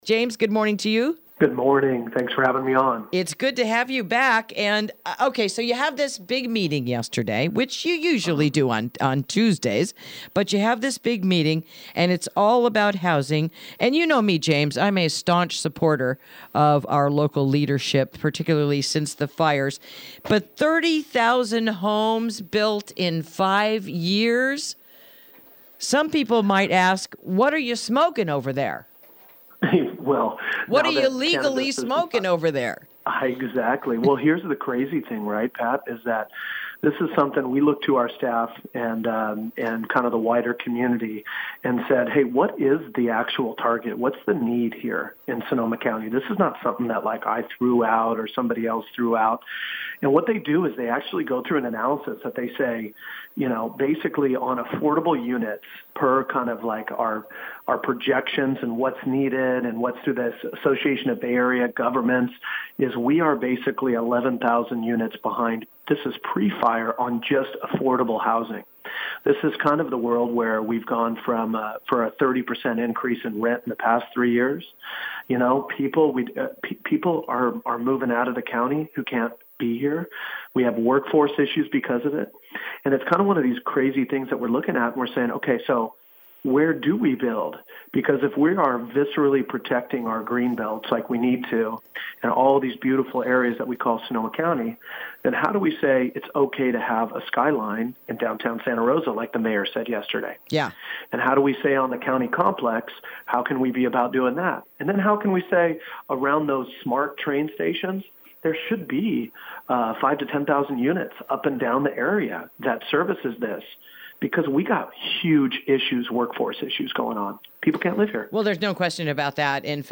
Interview: The Housing Plan Going Forward in the Post-Wildfire Era